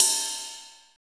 HARDRIDEEDG.wav